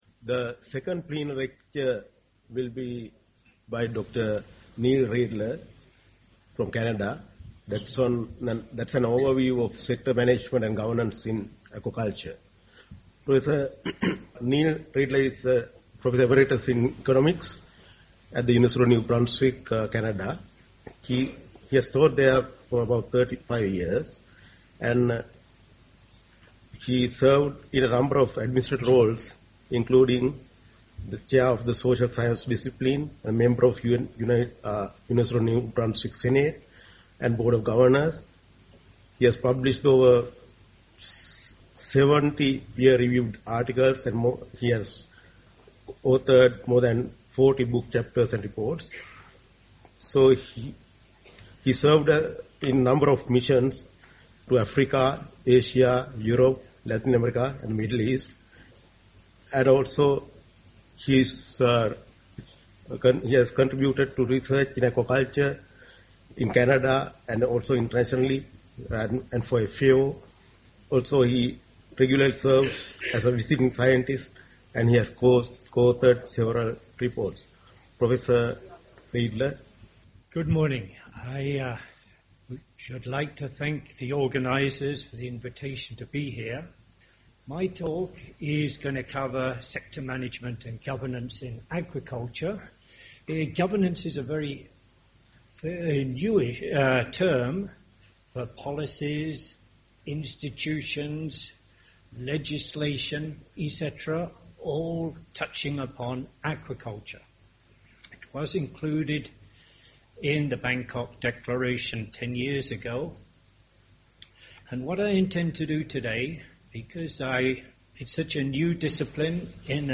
Presentation on governance enhancing social benefits of aquaculture